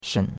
shen3.mp3